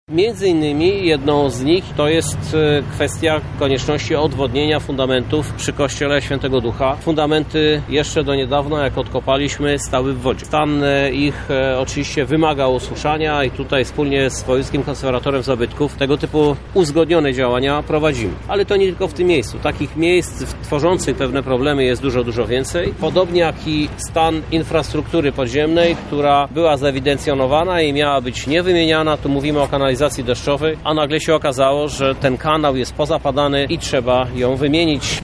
– Przy przebudowie deptaka budowlańcy napotykają pewne trudności – tłumaczy prezydent Lublina, Krzysztof Żuk: